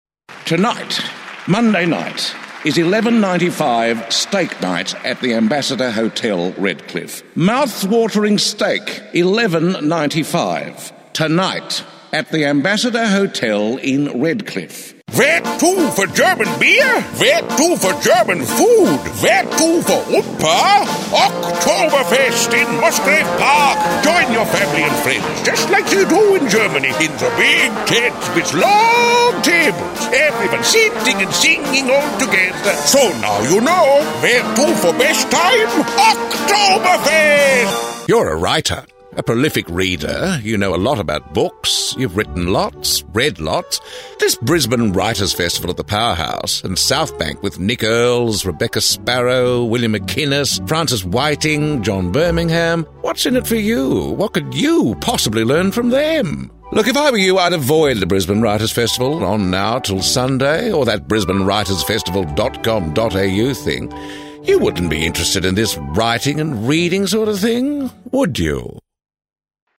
French accent is also a specialty when required, and Indian.
Full-blown "ocker" Aussie accents are also a specialty.